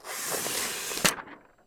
Colocar papel en una máquina de escribir electrónica
máquina de escribir
Sonidos: Oficina